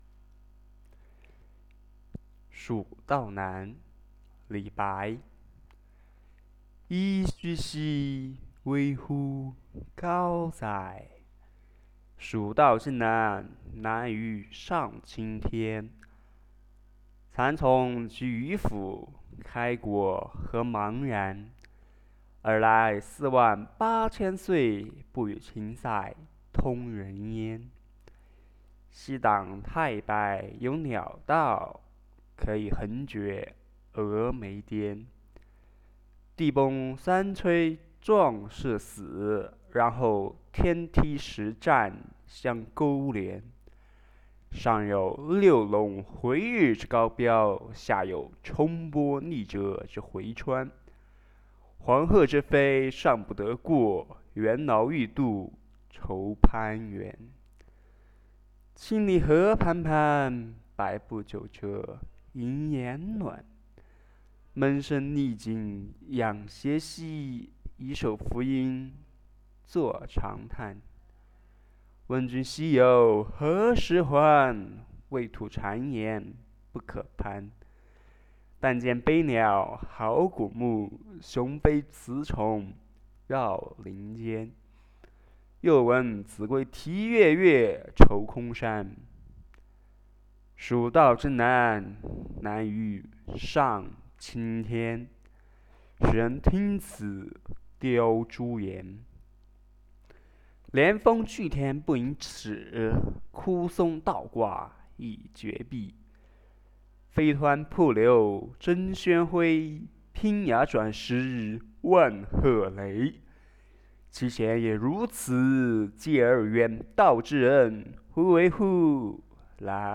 蜀道难-朗读
第一次朗读古诗，还有很大的进步空间 比如说气息，段落间隔，换气声